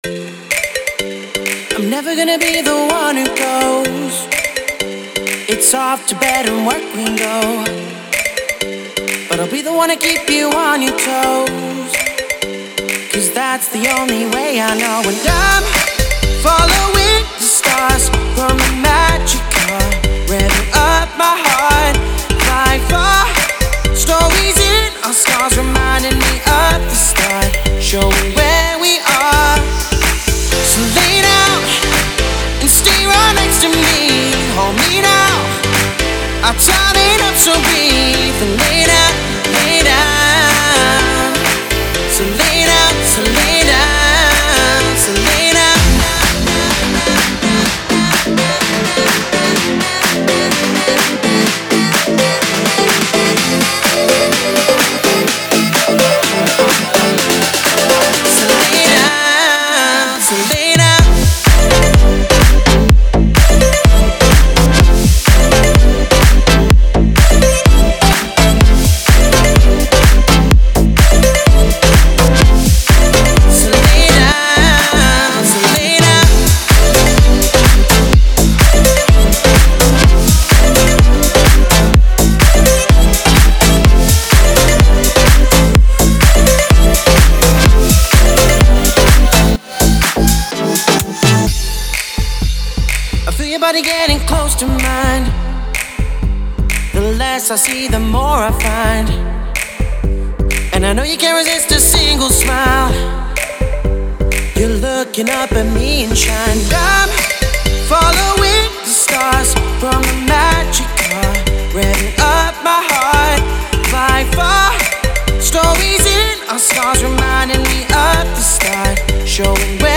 это энергичный трек в жанре хип-хоп